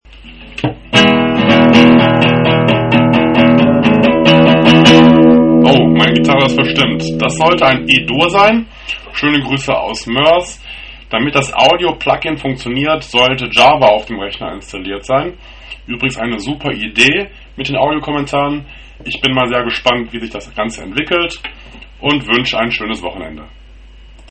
Ich teste mal und sende ein E-Dur.